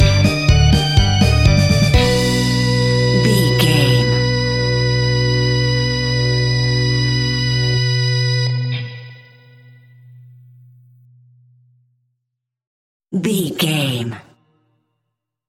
Diminished
scary
tension
ominous
dark
haunting
eerie
electric organ
piano
bass guitar
drums
creepy
horror music